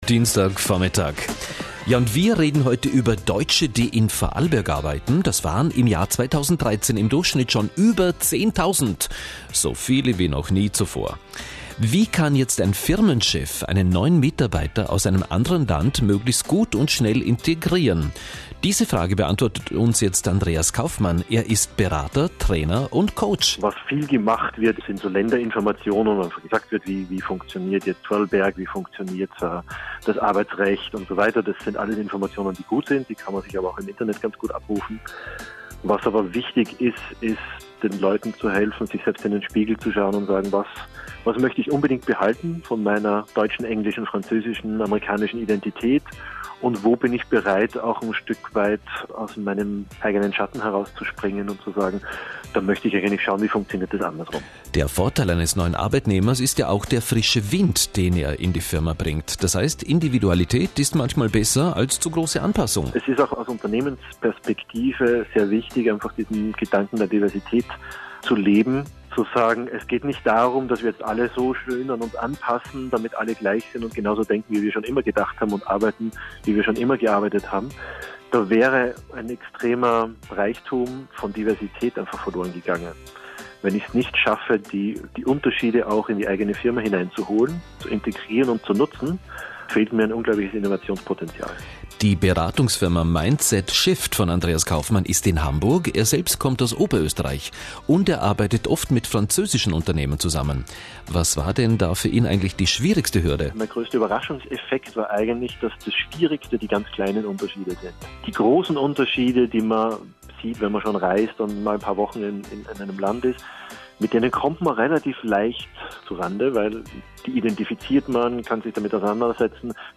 For german speaking readers: Listen to a short radio interview on cultural training… Radio Interview – Radio Vorarlberg
radiosnippet.mp3